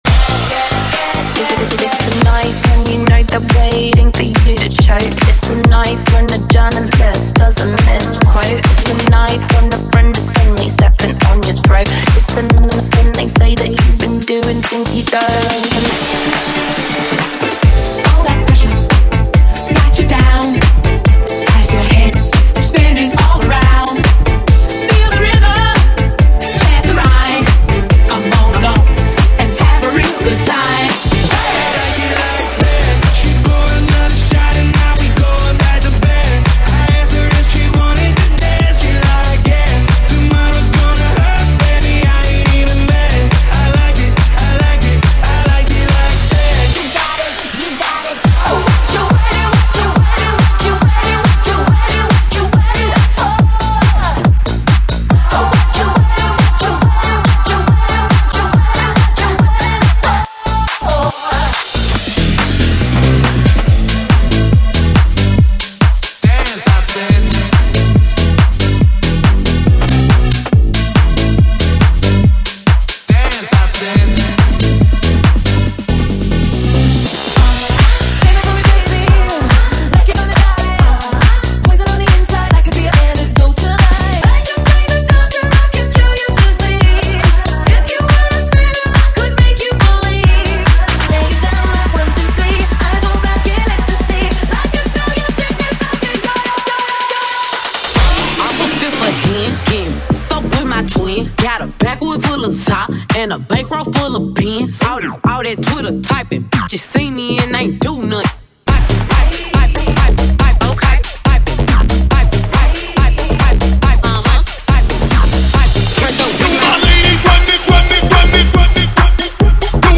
Less Pop & More Bass!
Harder Club & Anthem Sounds Remixed 2025 Style!